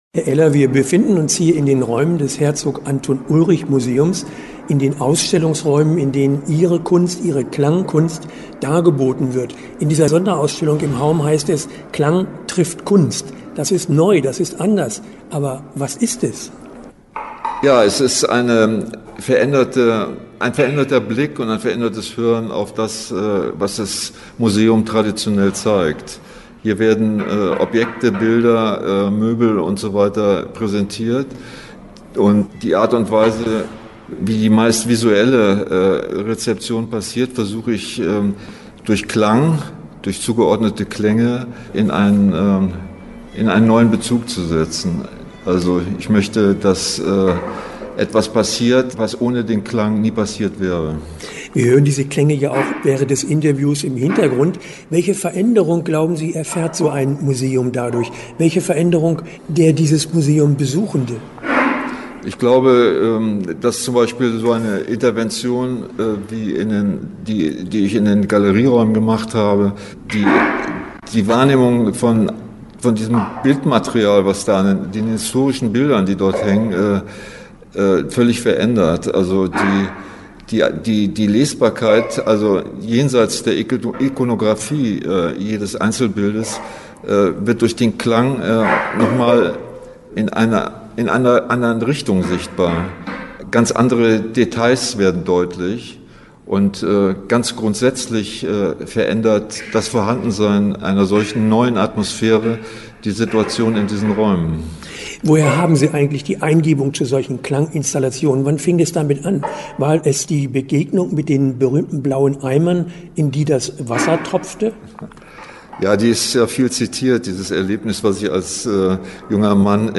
Interview-Klang-trifft-Kunst-HAUM_hok.mp3